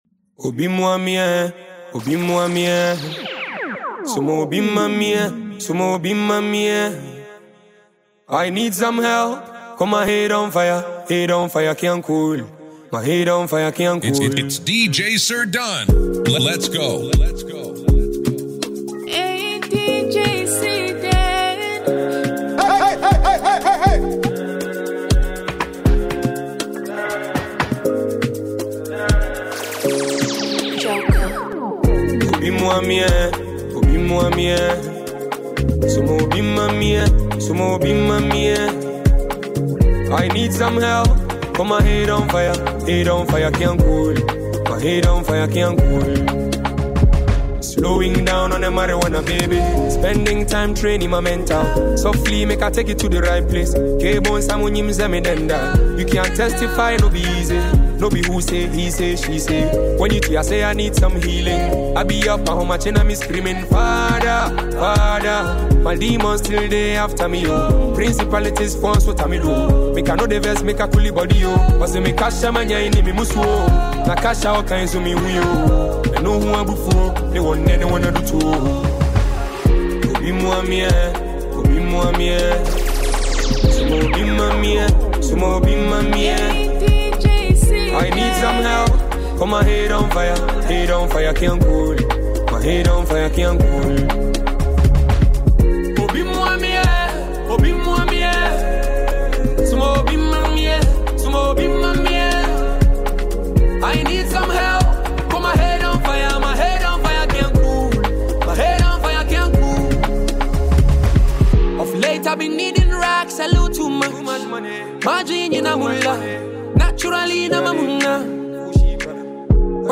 Afrobeat DJ Mixtape